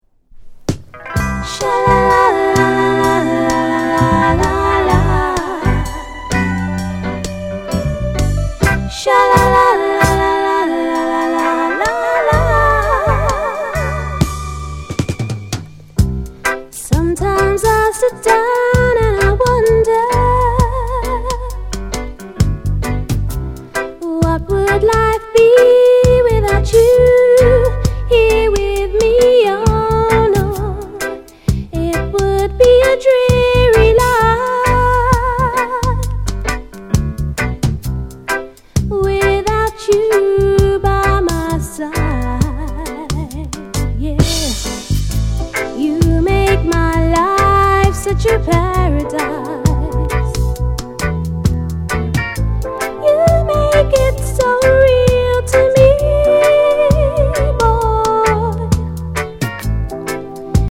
NICE LOVERS ROCK